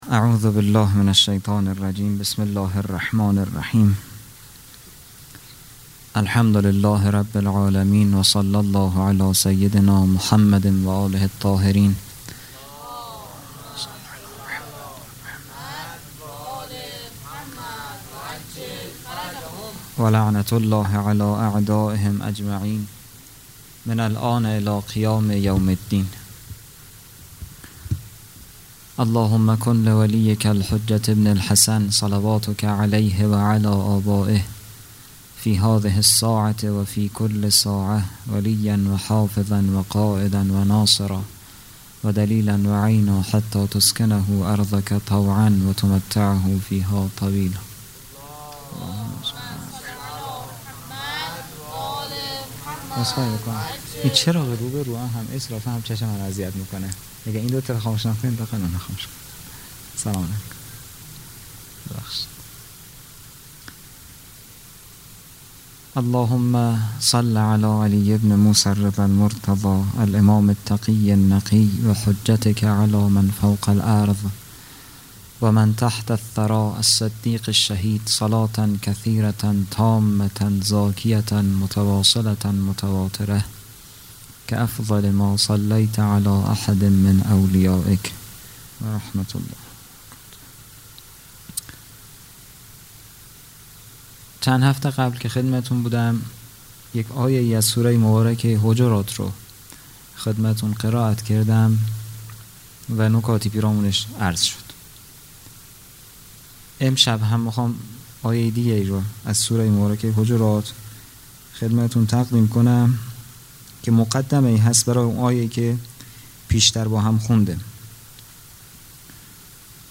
خیمه گاه - هیئت بچه های فاطمه (س) - سخنرانی | 2 تیر 1401
جلسۀ هفتگی